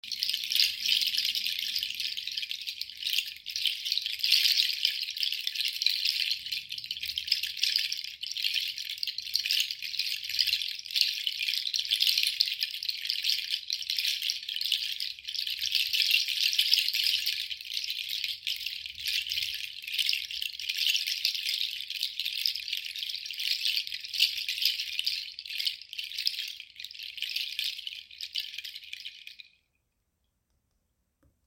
Seed Shaker Chacha
• Handmade in Indonesia from tropical, dried Chacha seeds
• Produces a warm sound, higher pitch than Pangi
Chacha seeds produce clear, sharp tones that add a unique texture to your music.
• Material: Made from authentic tropical, dried Chacha seeds for vibrant, resonant tones